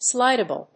音節slíd・a・ble 発音記号・読み方
/‐əbl(米国英語)/